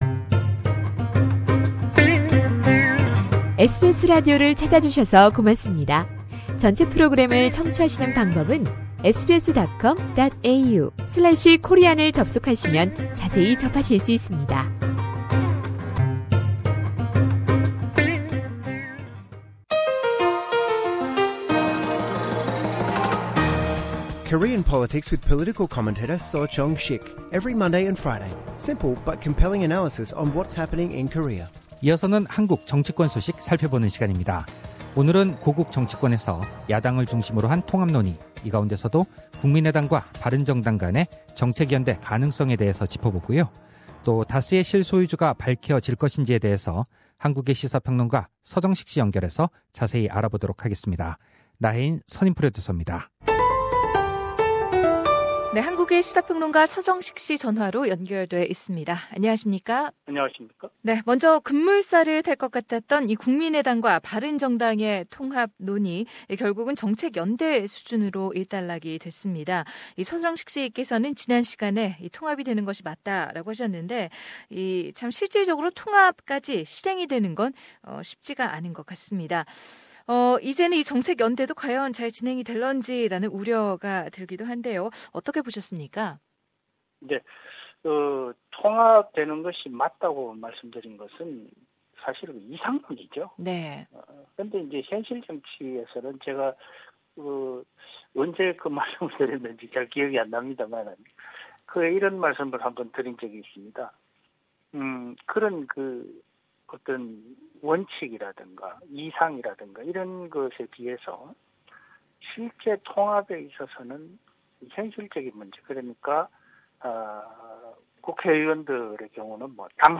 상단의 팟캐스트를 통해 전체 인터뷰를 들으실 수 있습니다.